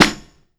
Church Snare.wav